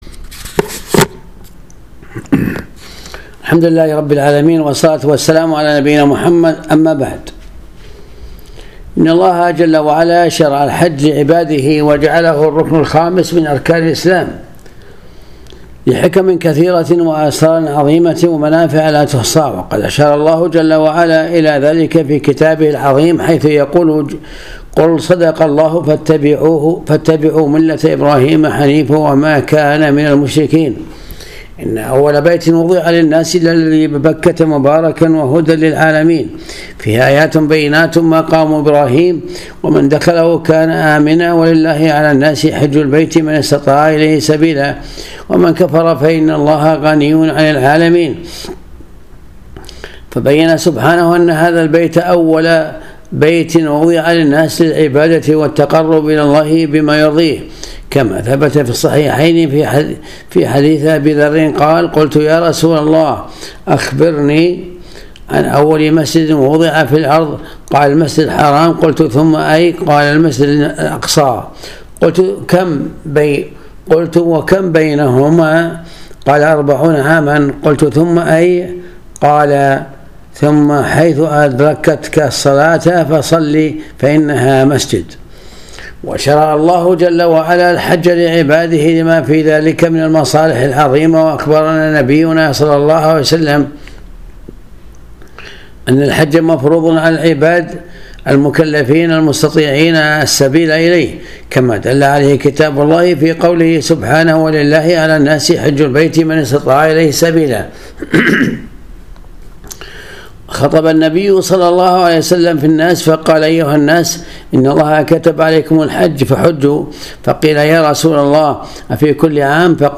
الدرس 182 الجزء الخامس من أهداف الحج